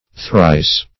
thrice - definition of thrice - synonyms, pronunciation, spelling from Free Dictionary
Thrice \Thrice\ (thr[imac]s), adv.
thrice.mp3